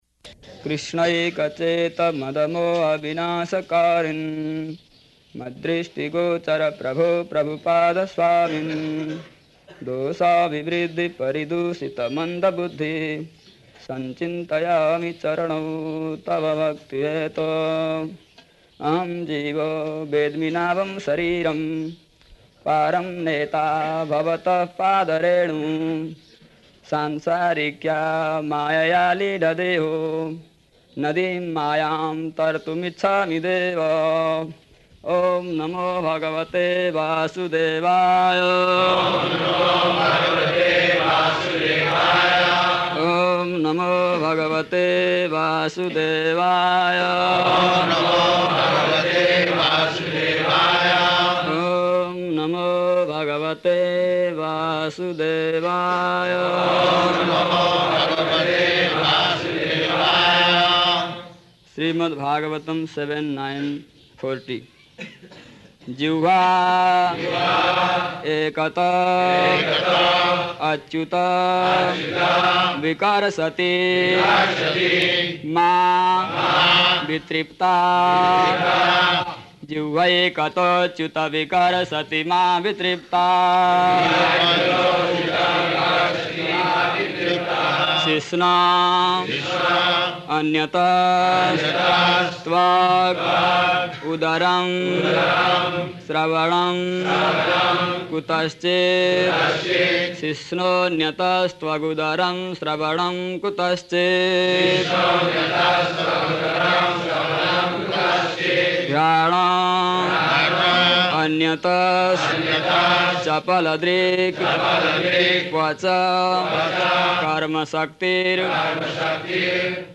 March 18th 1976 Location: Māyāpur Audio file